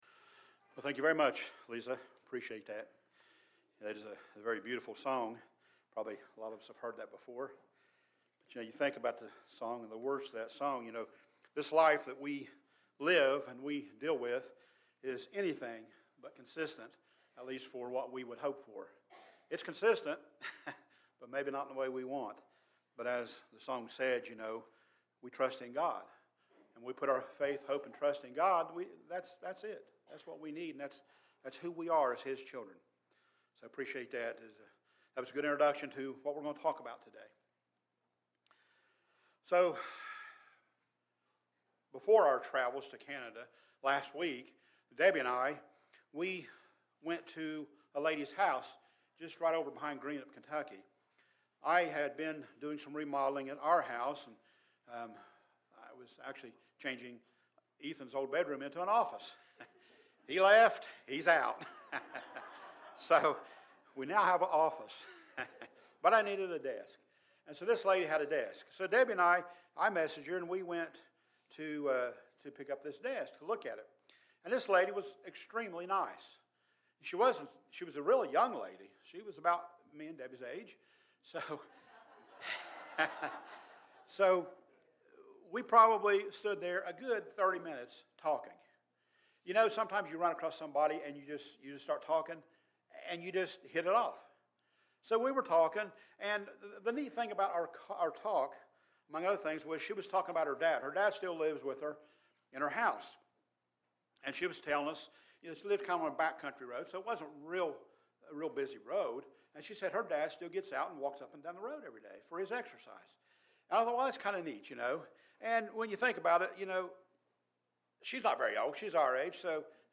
Sermons
Given in Paintsville, KY Portsmouth, OH